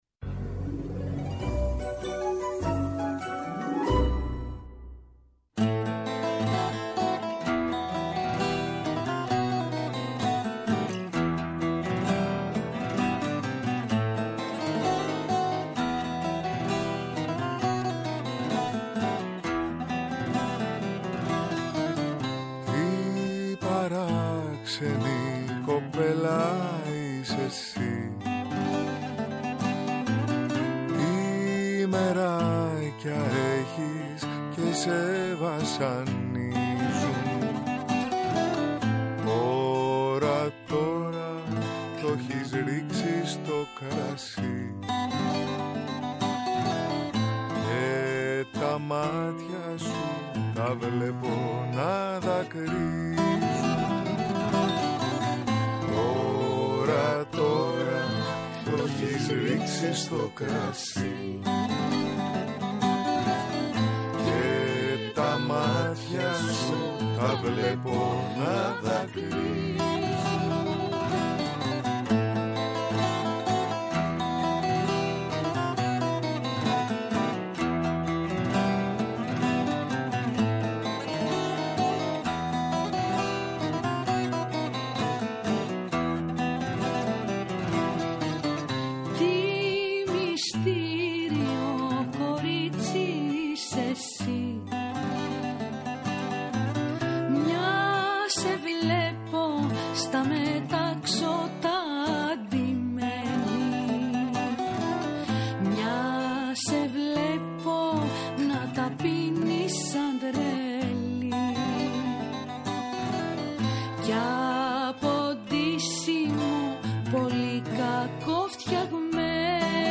Μία ραδιοφωνική ώρα